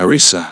synthetic-wakewords
ovos-tts-plugin-deepponies_Ryotaro Dojima_en.wav